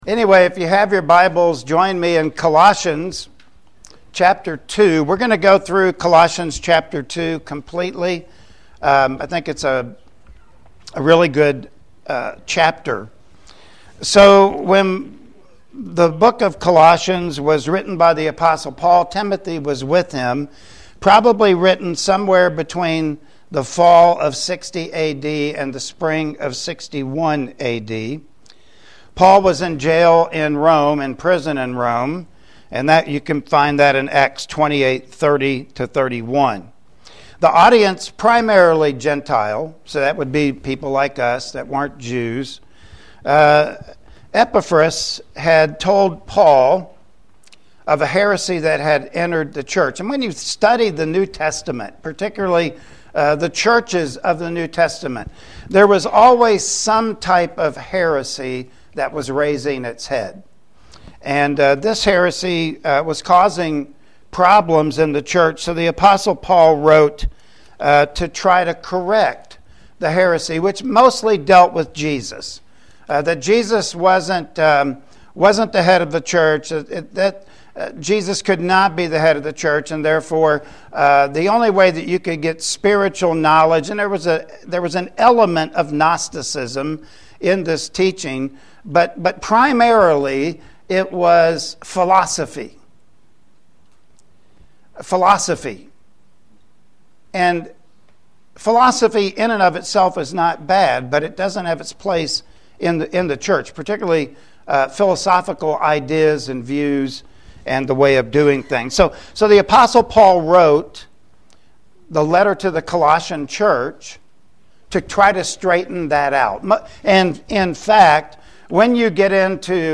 Colossians Chapter 2 Passage: Colossians 2:1-3 Service Type: Sunday Morning Worship Service Topics